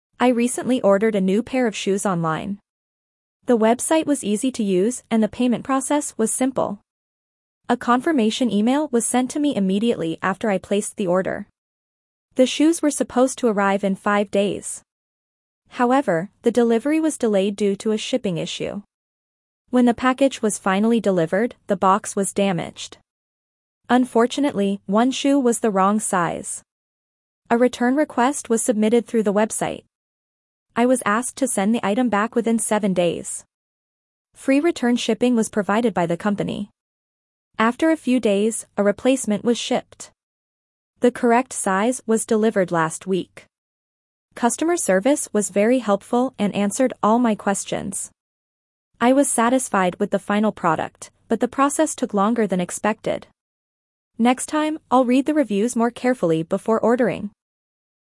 Dictation B1 - Technology
Your teacher will read the passage aloud.